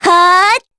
Talisha-Vox_Casting3_kr.wav